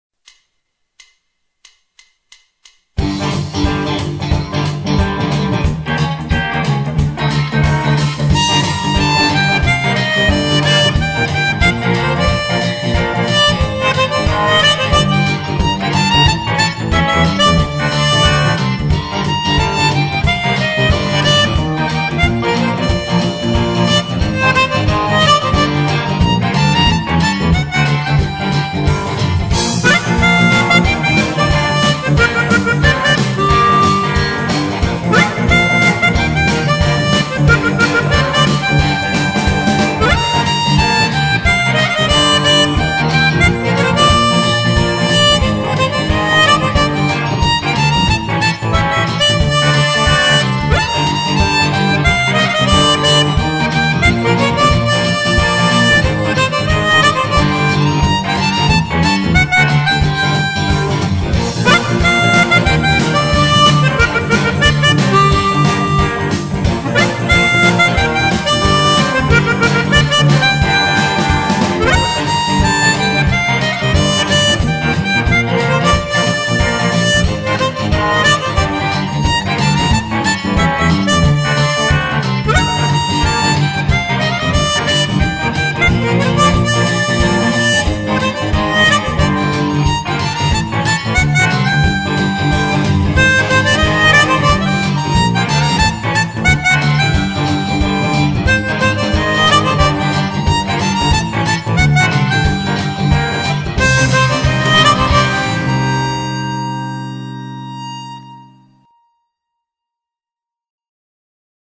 Ca décoiffe à 8H30, même quand on a plus de cheveux.